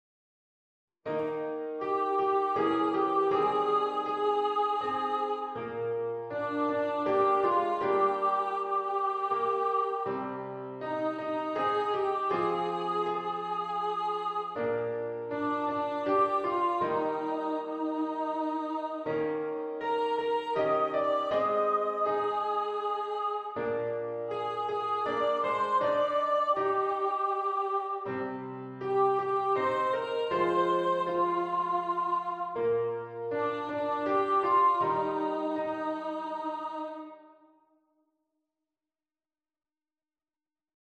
Rosa-de-Judá-Congregação-e-Piano.mp3